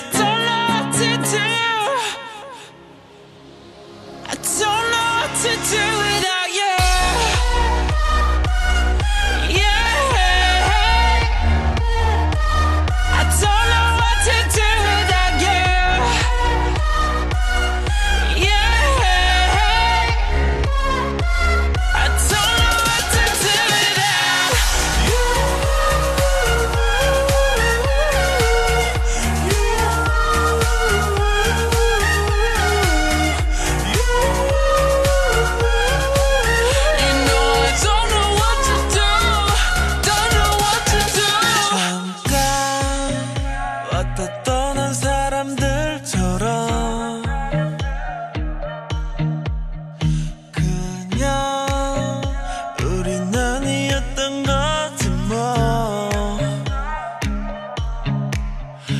Slowed down